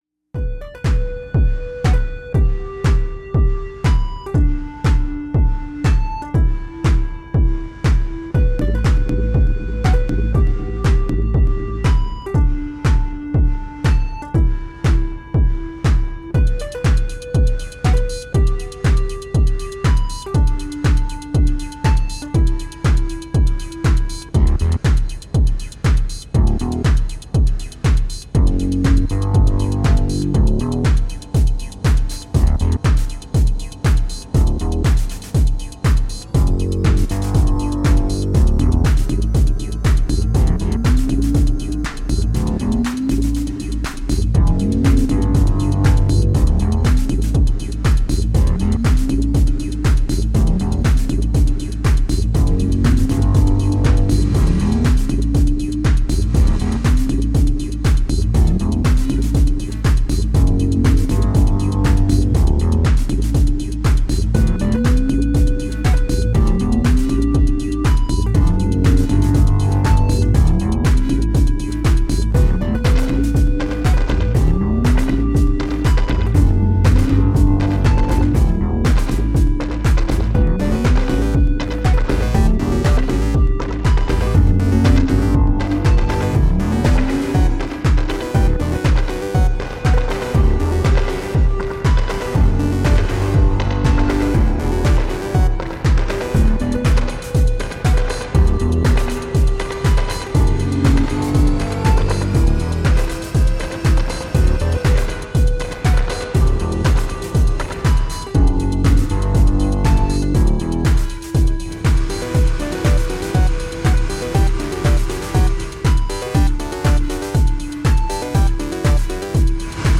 I’d like to blame the Juniper, but this was all me👤…